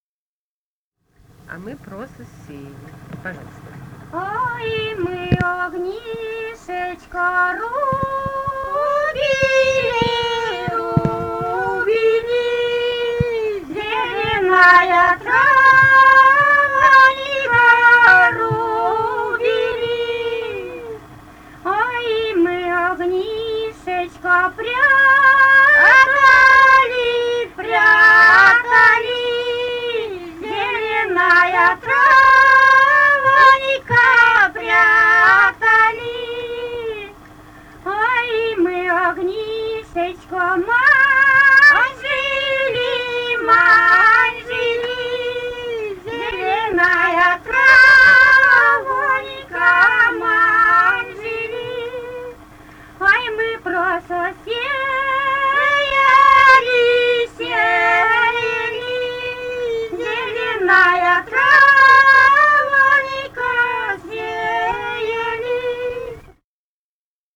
«Ой, мы огнищечко рубили» (весенняя хороводная).
Костромская область, с. Дымница Островского района, 1964 г. И0790-02